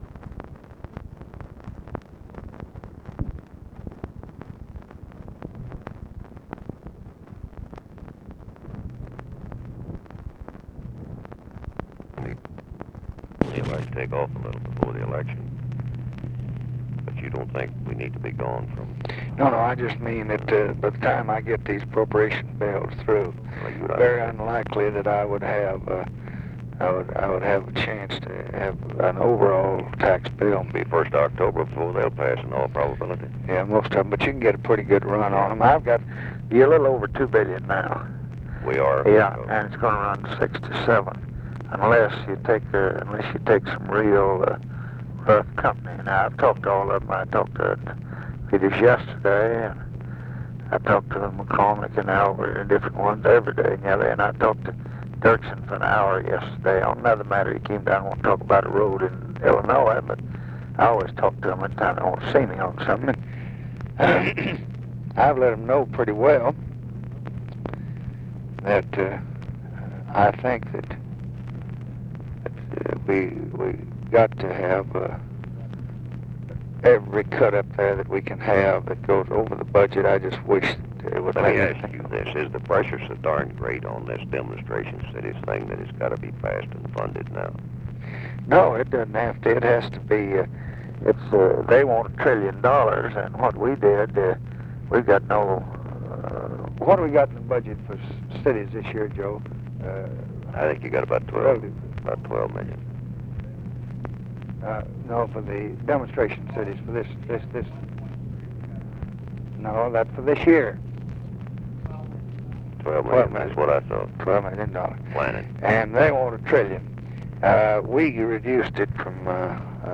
Conversation with WILBUR MILLS and OFFICE CONVERSATION, September 2, 1966
Secret White House Tapes